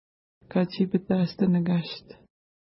Image Not Available ID: 353 Longitude: -58.9200 Latitude: 53.9285 Pronunciation: ka:tʃi:pəta:stnəka:ʃt Translation: Island with a Pointed Mountain (small) Official Name: St. John Island Feature: island